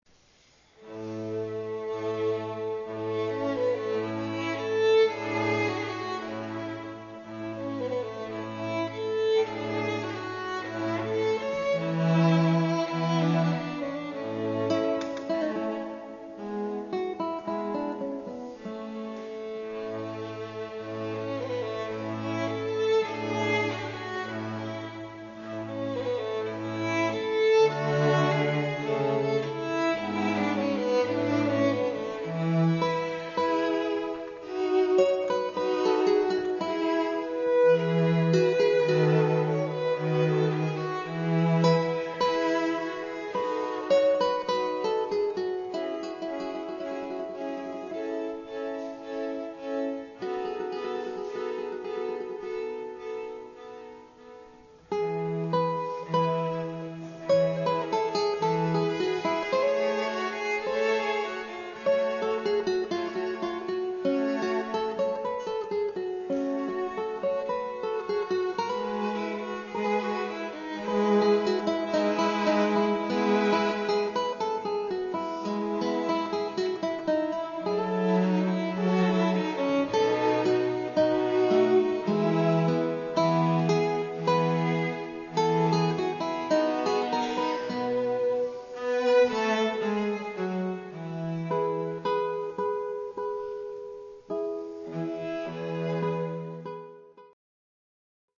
Adagio from Quartetto in D (live) sample 1'38'' (guitar and string trio)